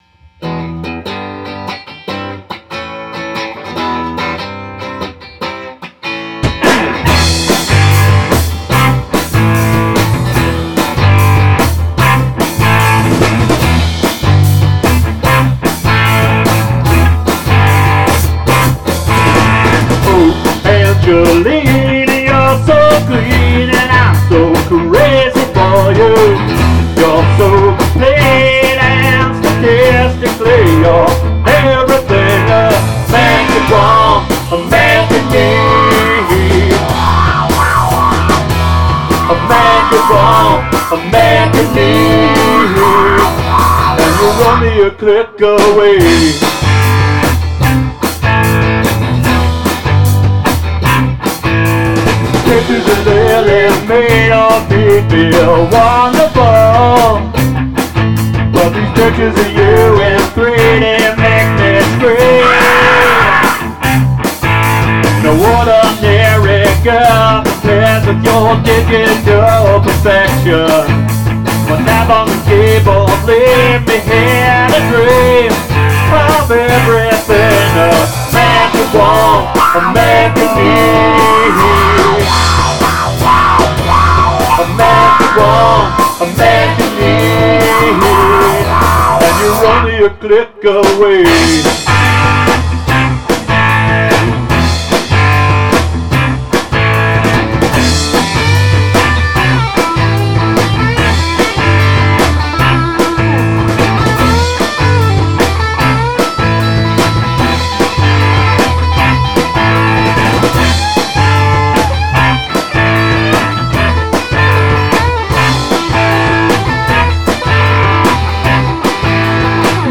unknownIt’s the only song where I ever got to use my Heil Talk Box, a guitar effect made notorious by Joe Walsh and Peter Frampton.